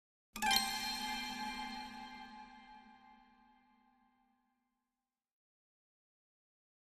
String Pluck Chord Strike With Reverb 2